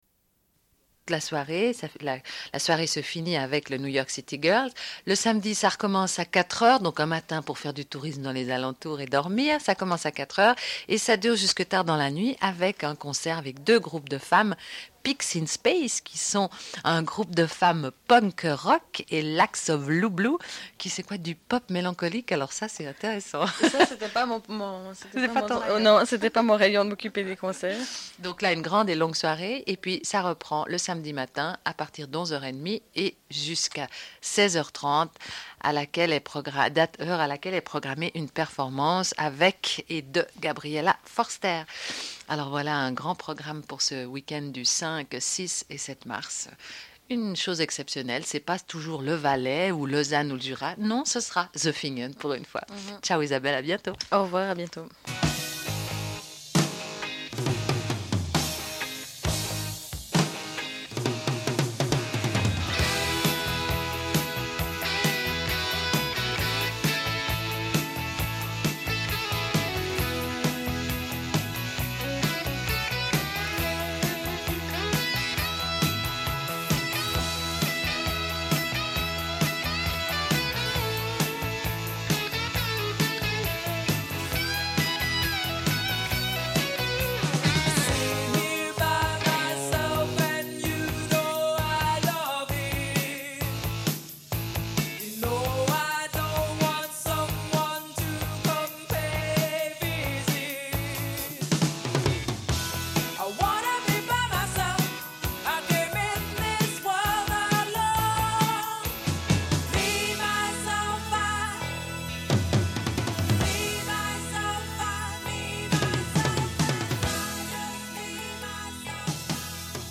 Une cassette audio, face A31:19
Radio